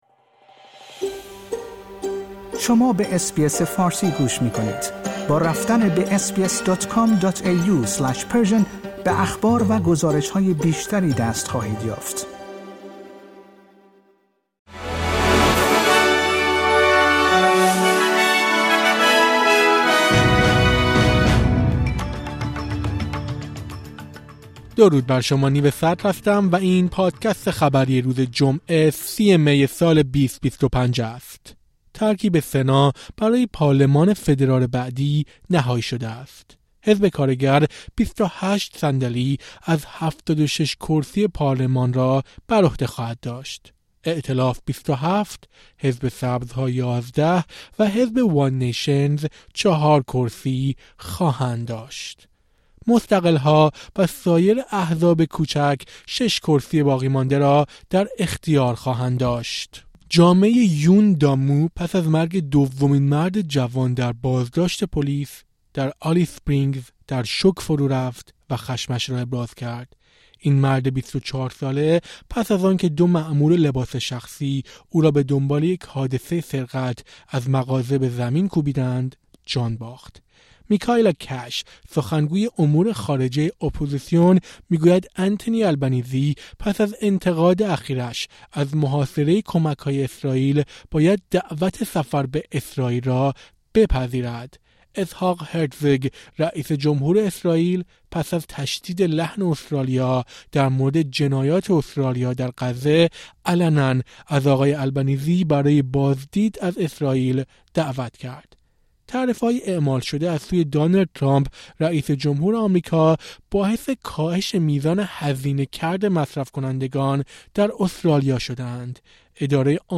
در این پادکست خبری مهمترین اخبار روز جمعه ۳۰ مه ارائه شده است.